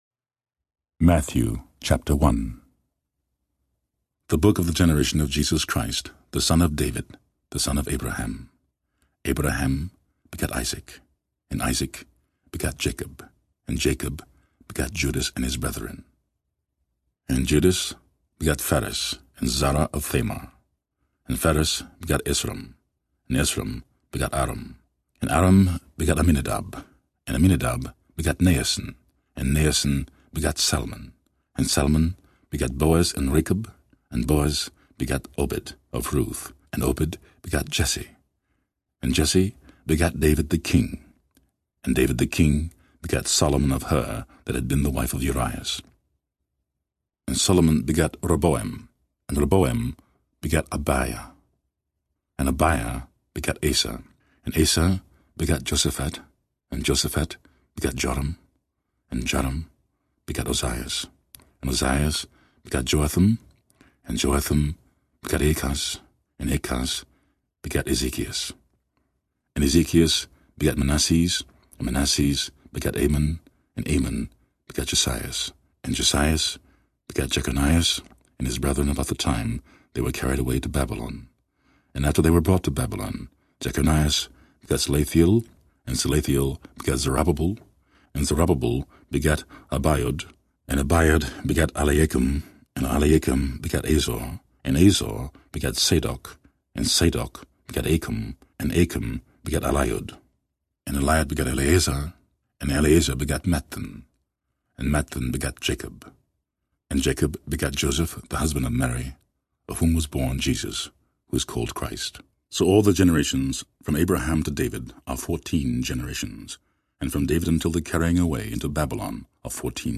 Relax to more than nineteen soothing hours of inspirational listening with crystal-clear audio of the New Testament read by one of the world’s most gifted actors of all time, James Earl Jones. Clearly marked CDs for easy trackingWord-for-word readings by James Earl JonesListen anywhere, anytime!This skilled and resonating interpretation by Mr. Jones has been hailed as one of the greatest spoken-word New Testament recordings of all time.
James Earl New Testament BS Sample.mp3